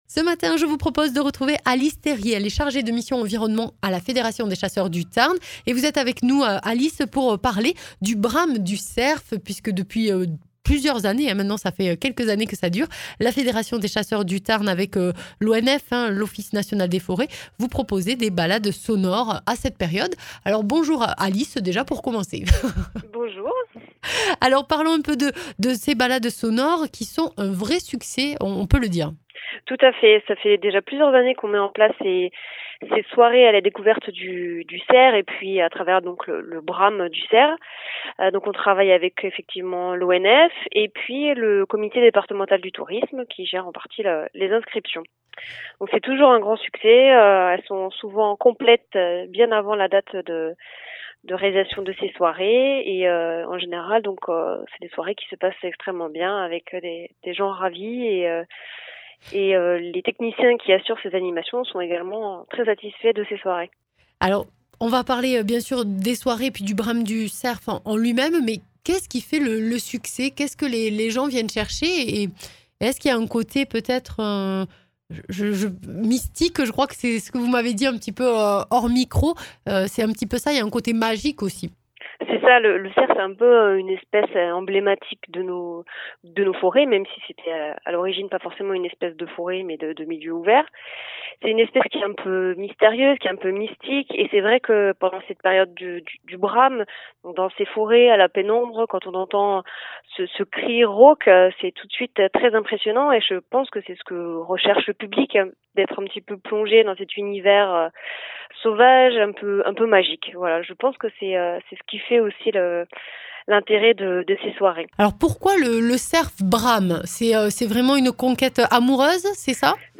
Brame du cerf
Balade sonore en forêt de Grésigne dans le Tarn pour entendre le brame du cerf.
Interviews